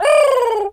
pigeon_call_angry_07.wav